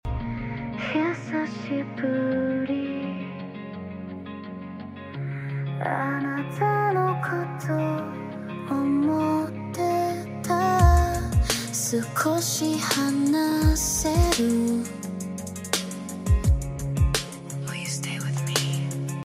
Jpop song